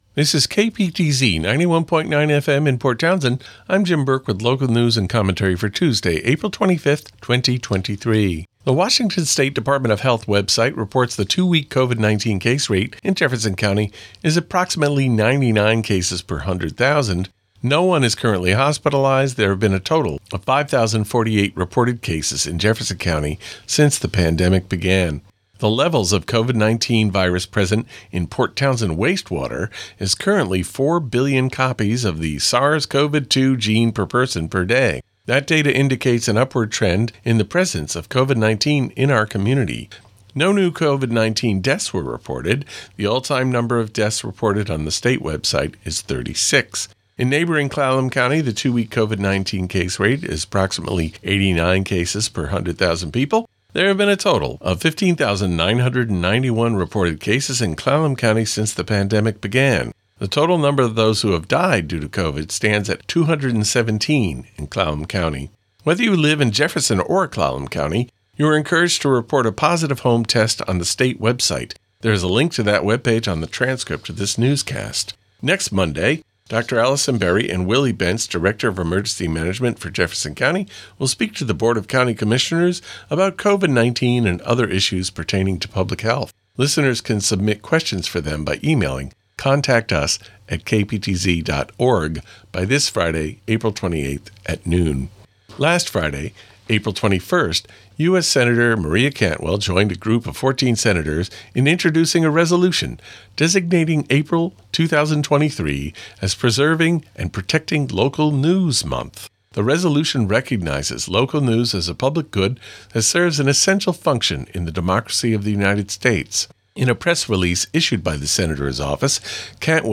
230425Local News Tuesday